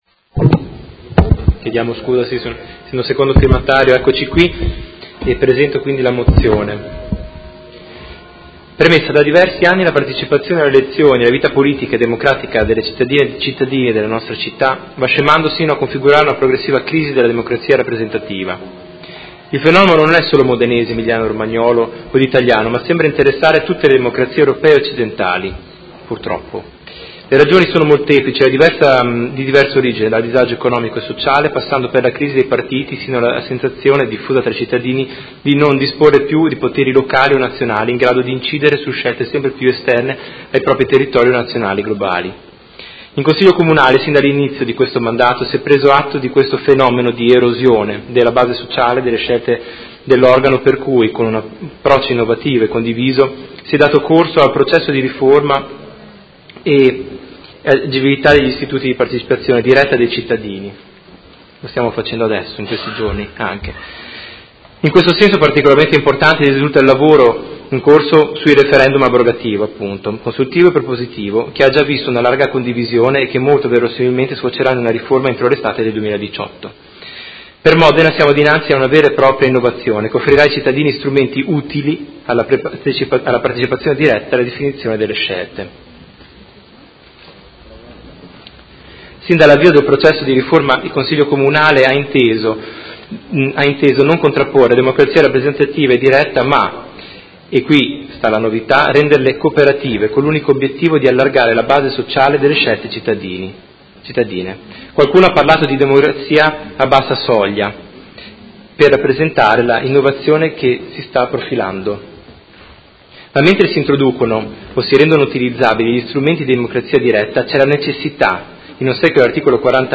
Seduta del 10/05/2018 Mozione presentata dal Gruppo Consiliare Art.1-MDP/Per me Modena avente per oggetto: Favoriree facilitare la partecipazione alla vita politica e democratica delle cittadine e dei cittadini modenesi